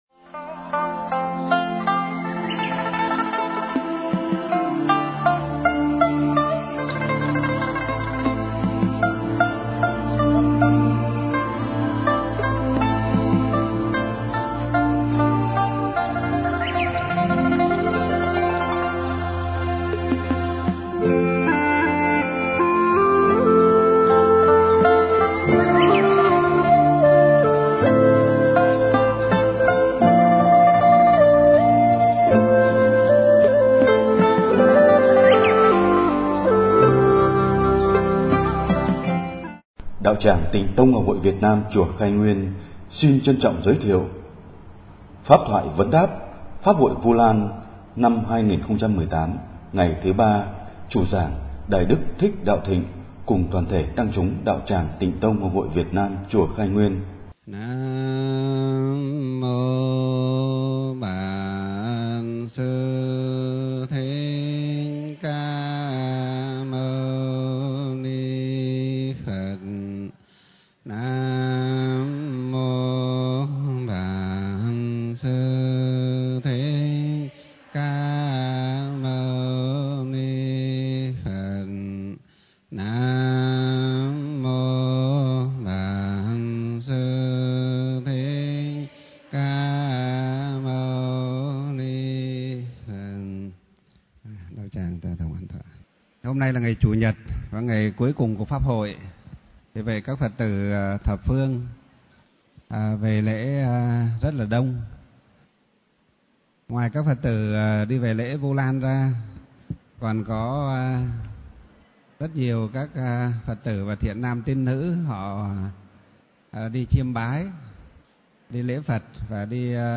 Thể loại: Giảng Pháp